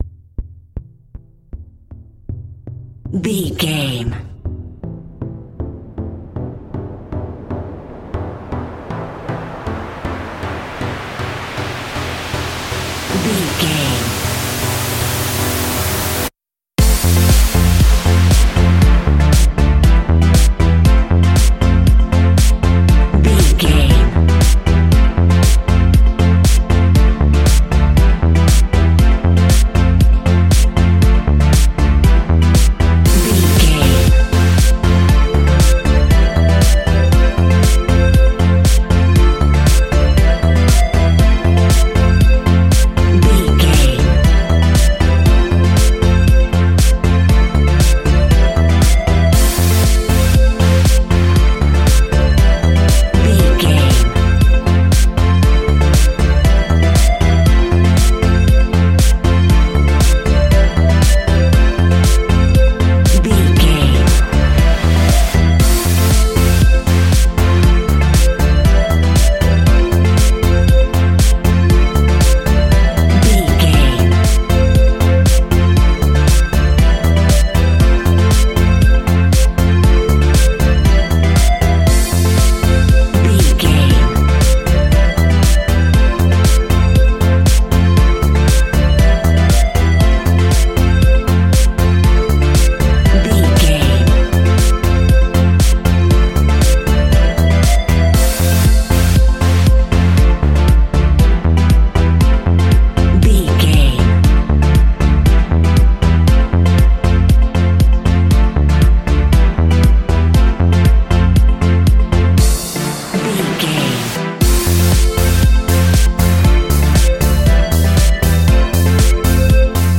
Aeolian/Minor
groovy
uplifting
futuristic
driving
energetic
repetitive
bouncy
drum machine
electronica
synth leads
synth bass
synth pad
robotic